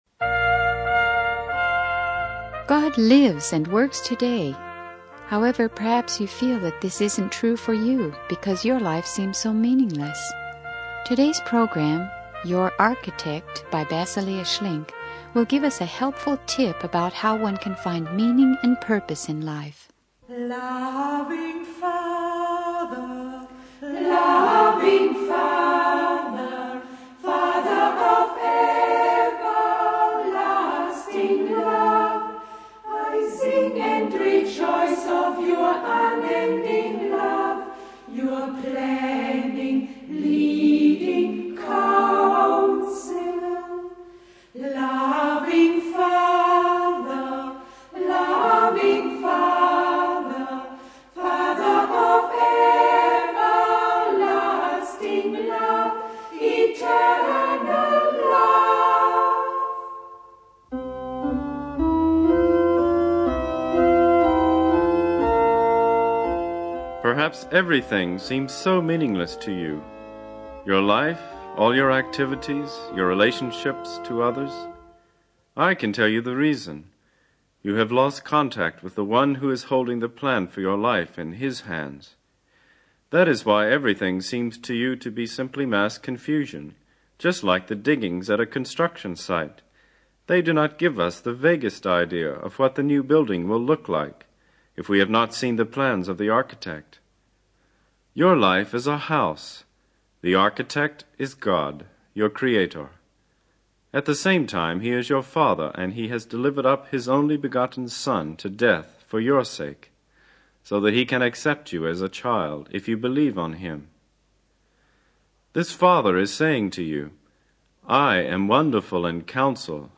Basilea Schlink's sermon encourages listeners to recognize God as the architect of their lives and to trust in His plans for true meaning and purpose.